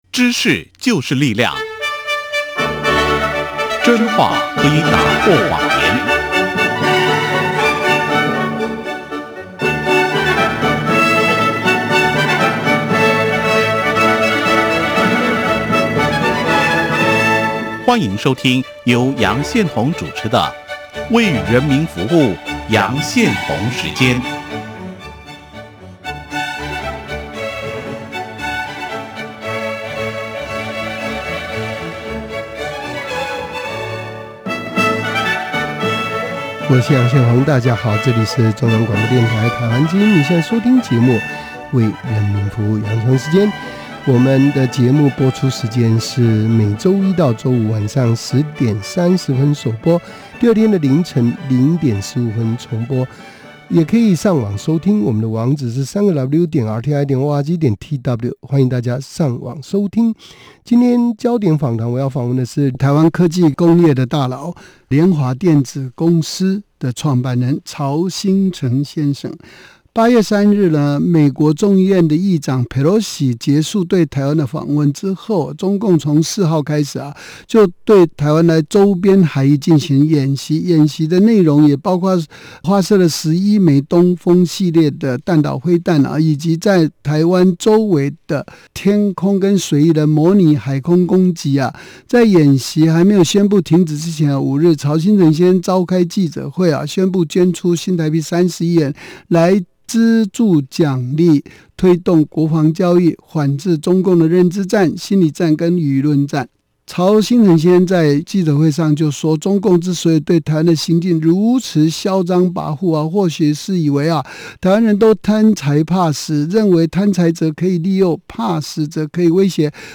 嘉賓：曹興誠先生/台灣科技工業的大老、「電子聯華電子公司」的創辦人